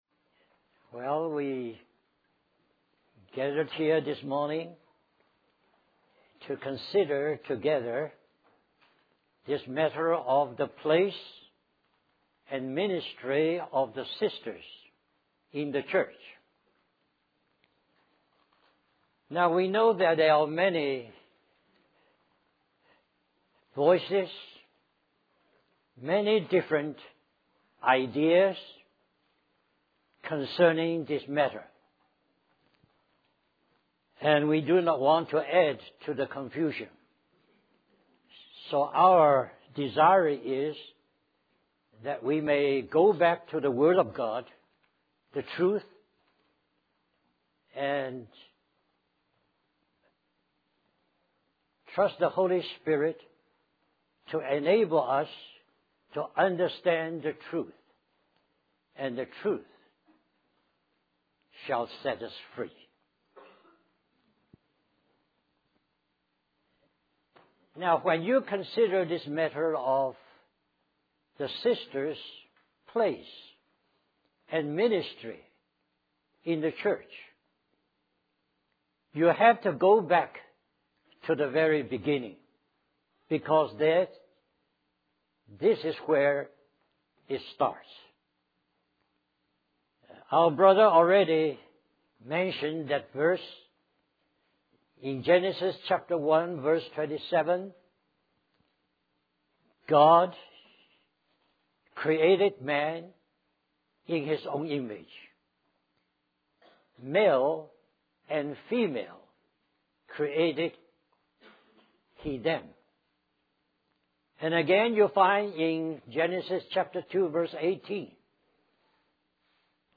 In this sermon, the speaker emphasizes the principle of Jesus Christ's submission to the will of his Father.